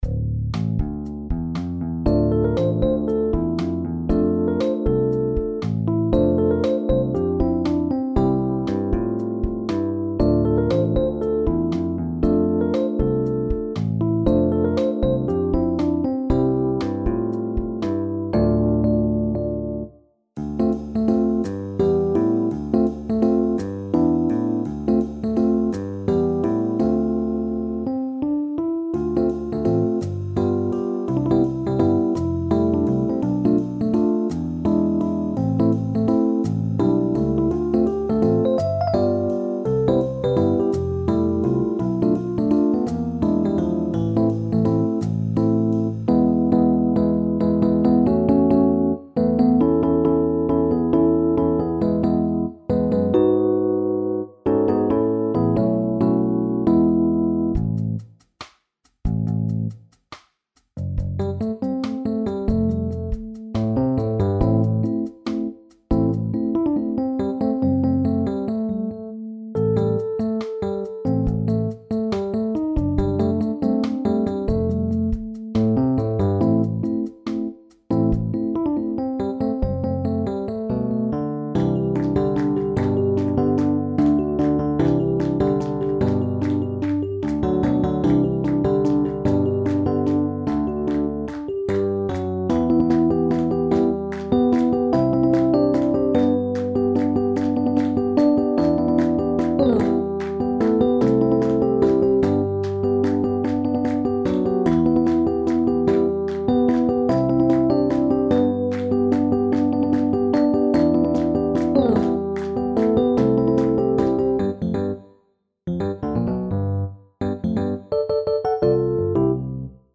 SSATB